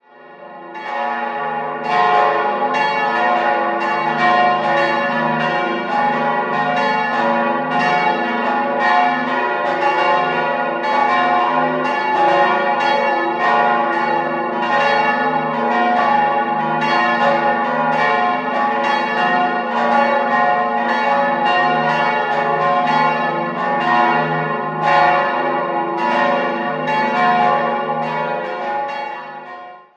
4-stimmiges Salve-Regina-Geläute: d'-fis'-a'-h'
bell
Bemerkenswertes Mischgeläute mit zwei sehr alten Glocken.
Regensburg_AlteKapelle.mp3